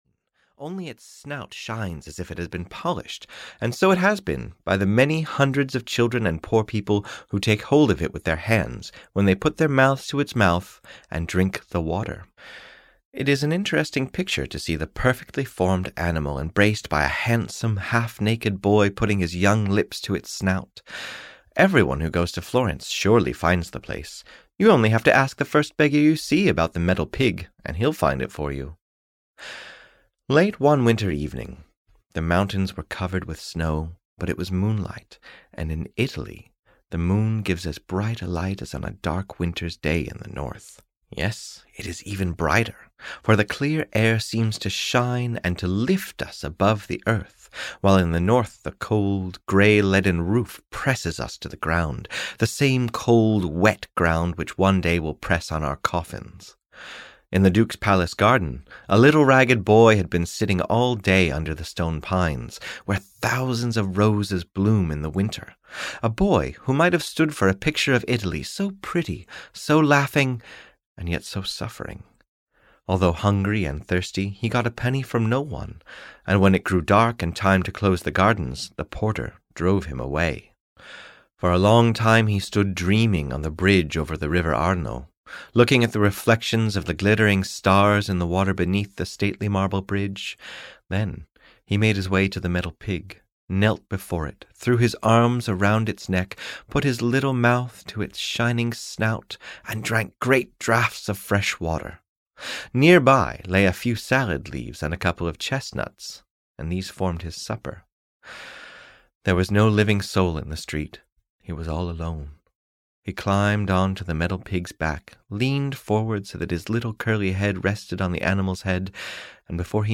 The Metal Pig (EN) audiokniha
Ukázka z knihy